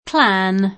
vai all'elenco alfabetico delle voci ingrandisci il carattere 100% rimpicciolisci il carattere stampa invia tramite posta elettronica codividi su Facebook clan [ klan ; ingl. klän ] s. m.; pl. (ingl.) clans [ klän @ ]